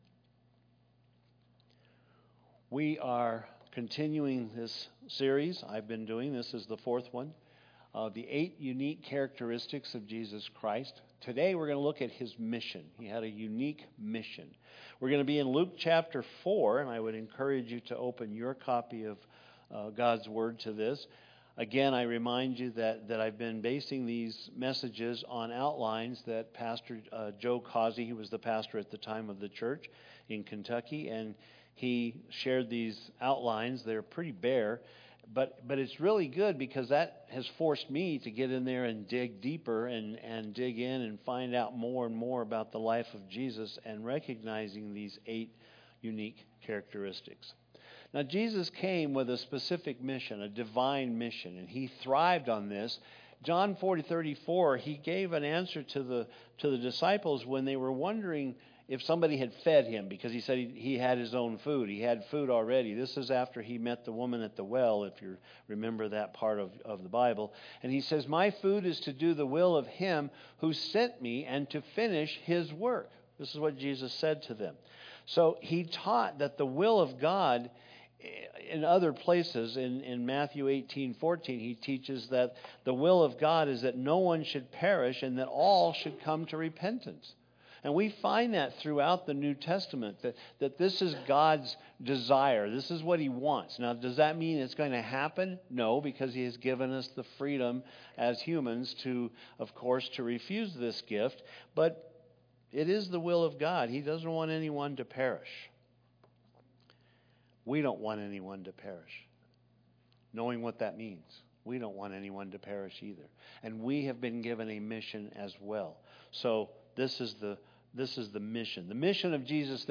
Eight Unique Characteristics of Jesus Christ - His Mission - Includes Communion